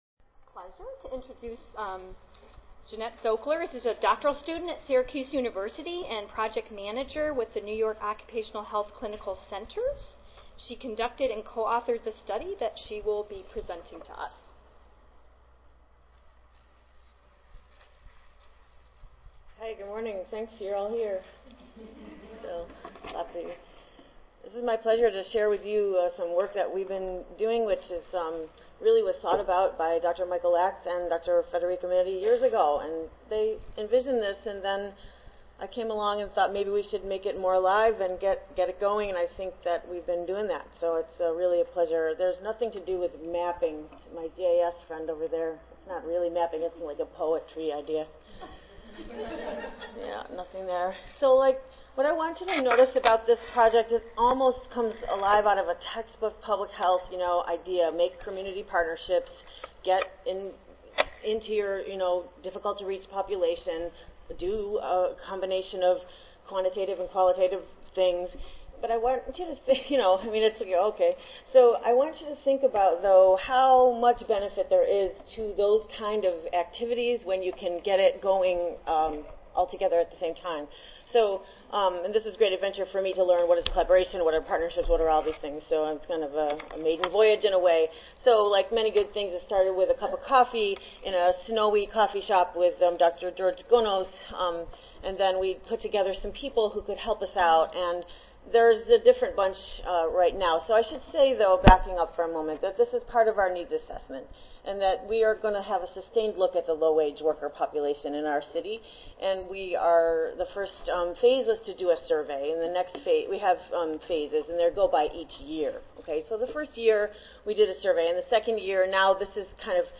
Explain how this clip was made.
142nd APHA Annual Meeting and Exposition (November 15 - November 19, 2014): Precarious work: day laborers and temp workers